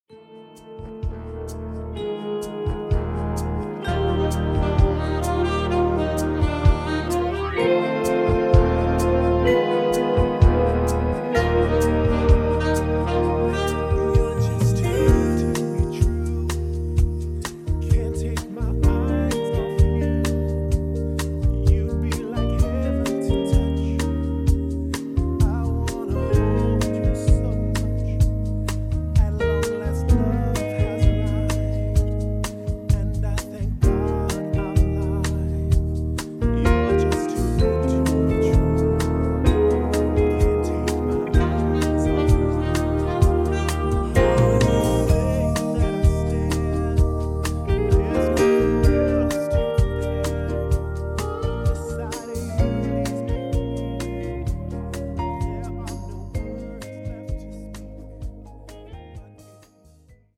음정 -1키 3:17
장르 가요 구분 Voice Cut